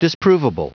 Prononciation du mot disprovable en anglais (fichier audio)